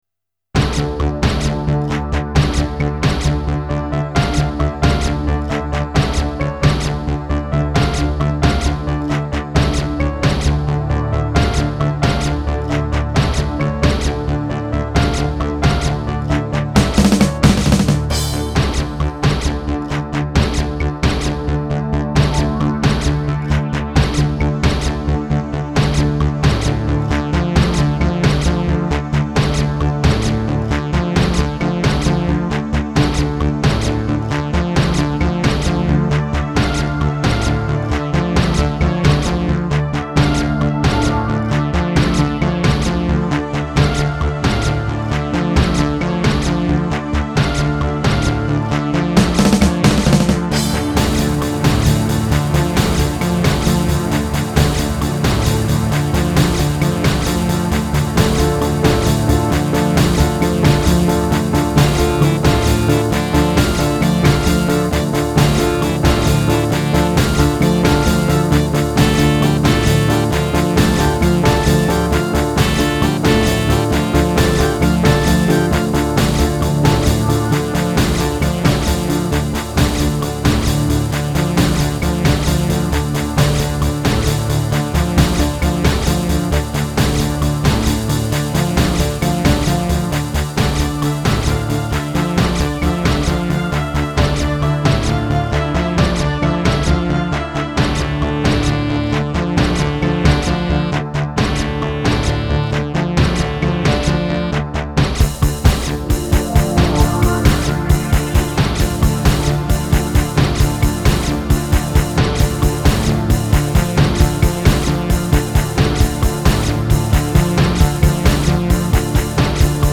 Solid infection rhythms with an international flavour